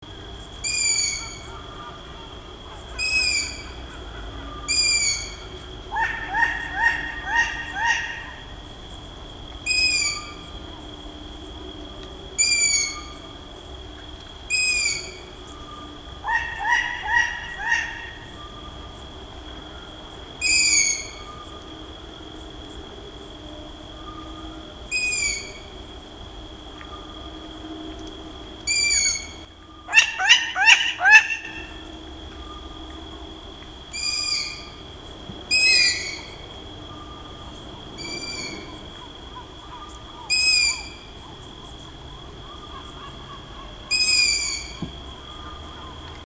Richiamo dei pulli e femmina (2j>&f>)
gufocomune.mp3